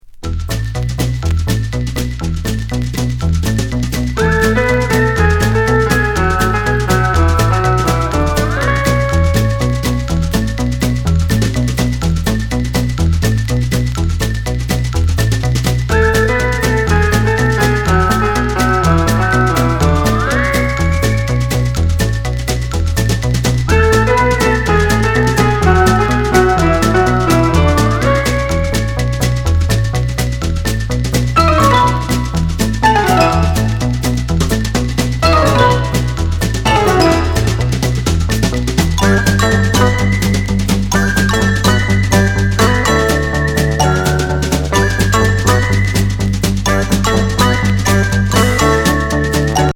アムステルダム発ひねくれアートロックグループ83年作。